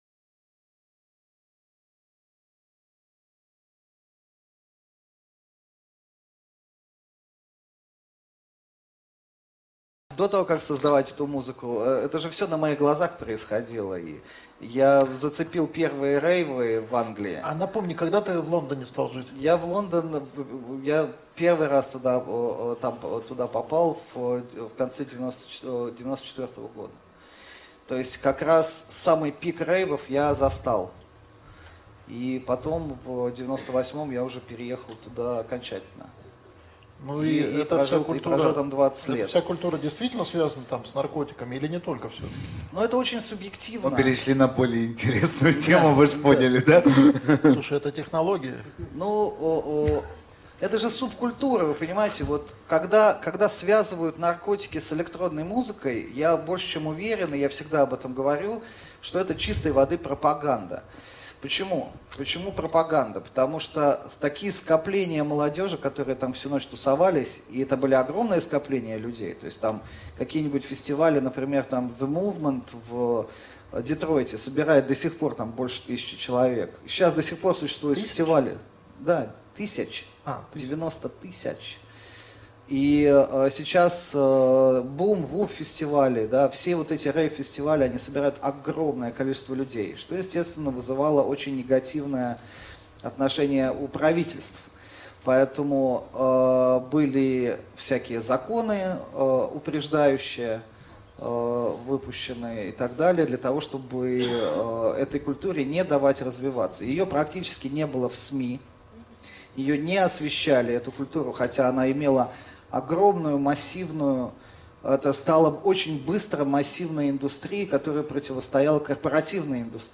Аудиокнига Музыка. История технологий или синергия разных. ч.2 | Библиотека аудиокниг